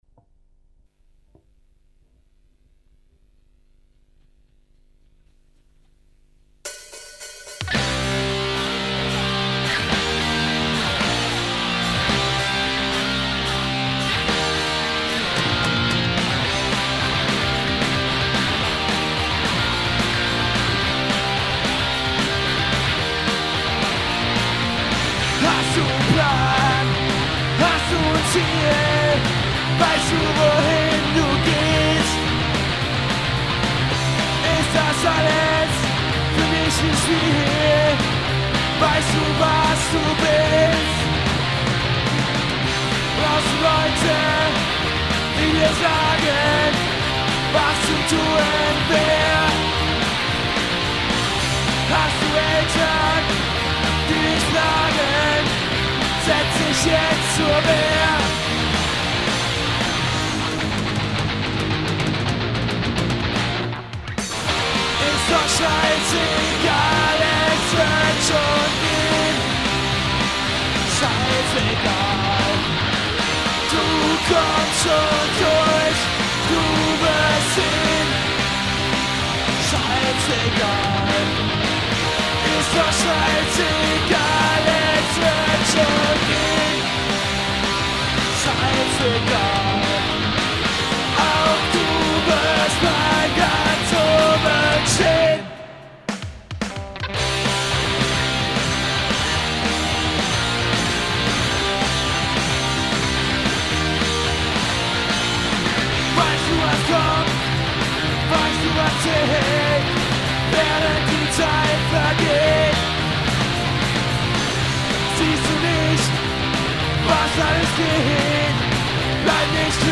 Melodic Punk Rock mit energiegeladenen deutschen Texten
E-Gitarre
Schlagzeug
Bass